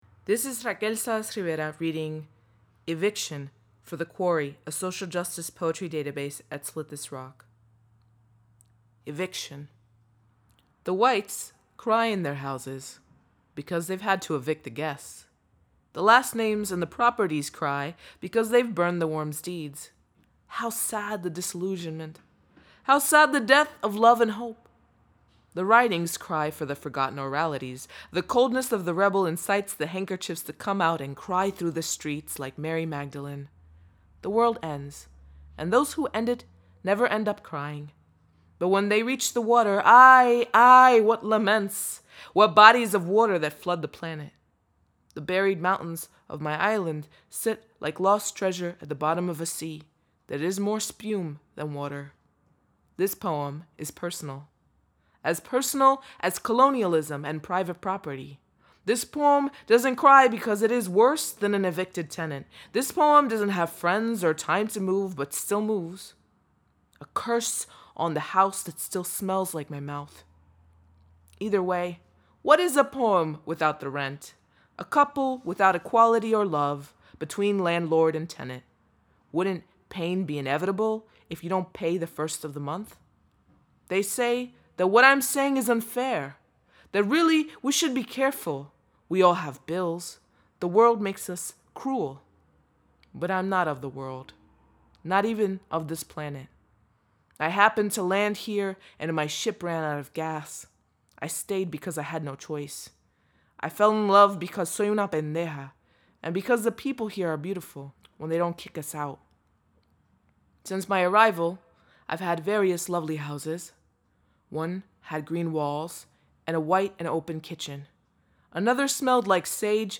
Listen as Raquel Salas Rivera reads "eviction."